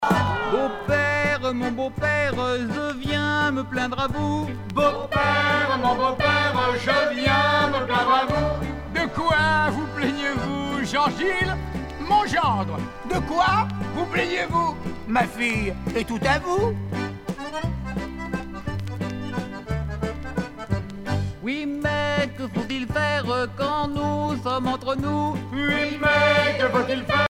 Genre dialogue
Pièce musicale éditée